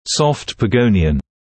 [sɔft pə’gəunɪən][софт пэ’гоуниэн]погонион мягких тканей (цефалометрический ориентир)